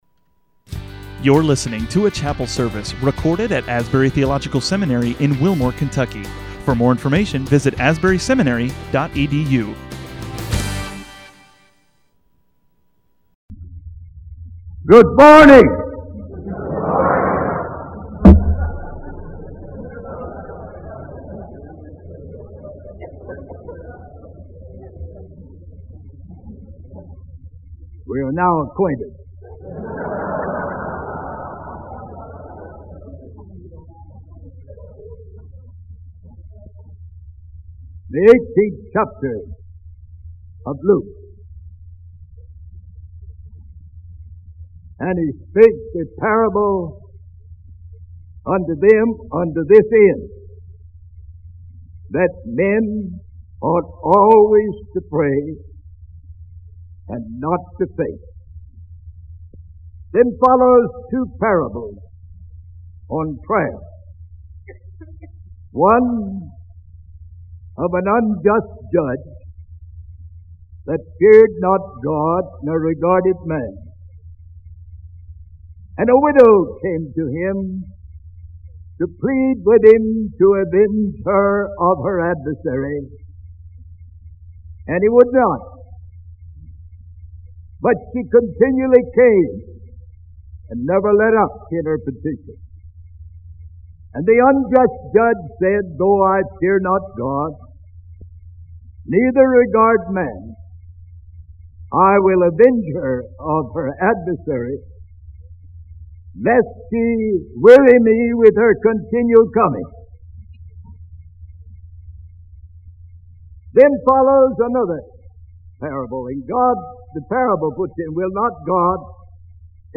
Faculty chapel services, 1982
Keywords Faculty, chapel, services, 1982, Prayer, Christianity, Bible, John, XVII, 17, Luke, XVIII, 18 Sermons Disciplines Christianity | Liturgy and Worship | Practical Theology Comments Content verified.